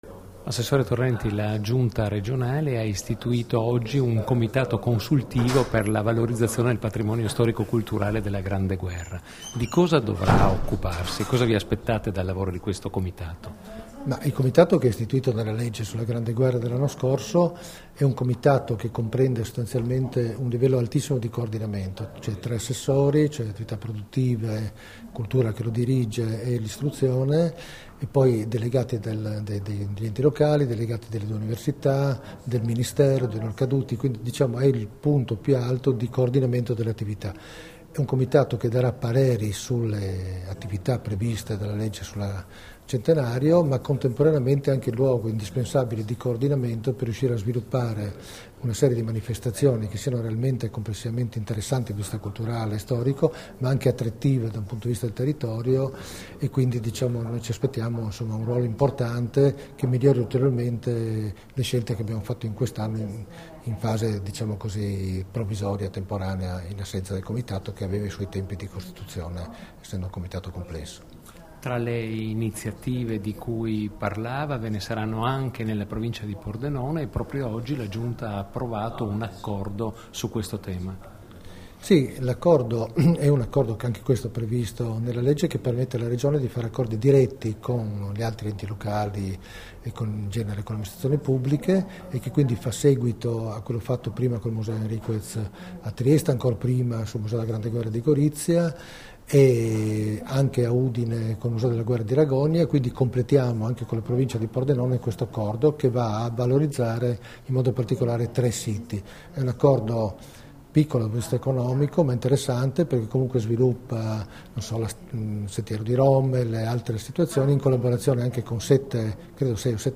Dichiarazioni di Gianni Torrenti (Formato MP3)
sull'istituzione del Comitato consultivo per la valorizzazione del patrimonio storico-culturale della Prima Guerra Mondiale, rilasciate a Trieste il 12 dicembre 2014